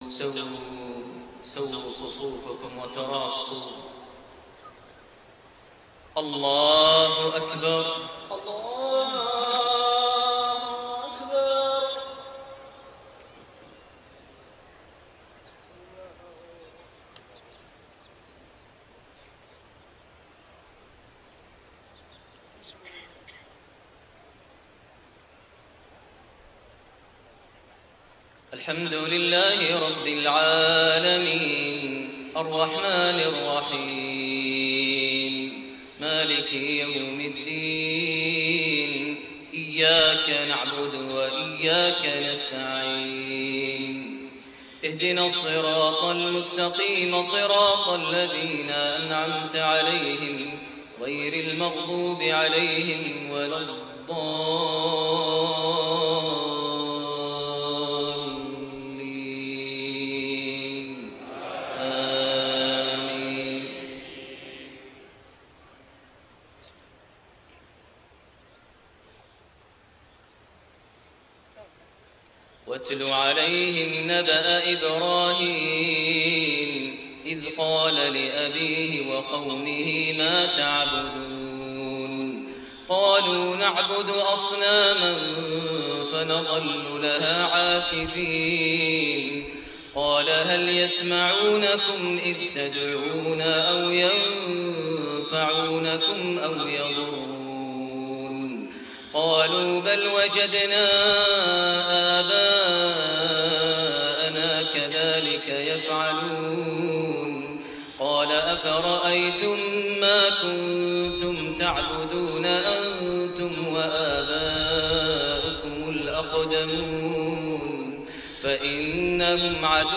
أول فرض | صلاة العشاء ٢٩ رجب ١٤٢٨هـ سورة الشعراء٦٩-١٠٤ > أول صلاة للشيخ ماهر المعيقلي في المسجد الحرام ١٤٢٨هـ > المزيد - تلاوات ماهر المعيقلي